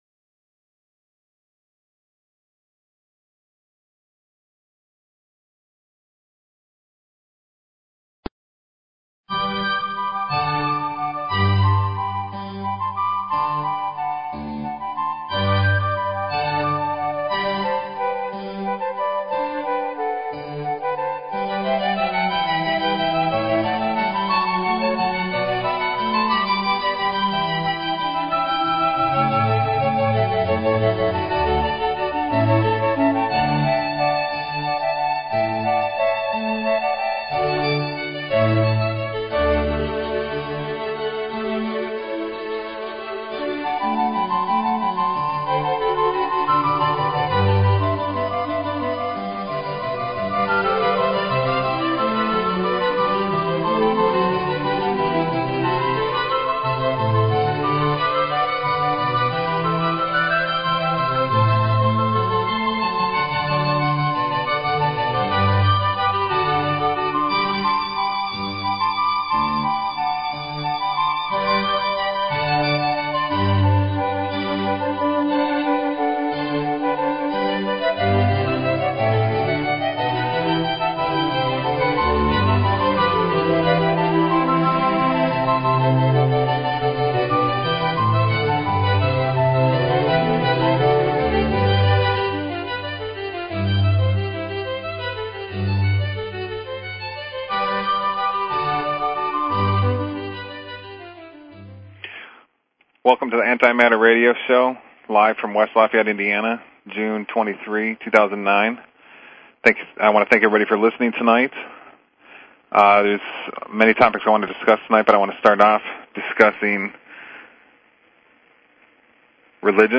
Talk Show Episode, Audio Podcast, The_Antimatter_Radio_Show and Courtesy of BBS Radio on , show guests , about , categorized as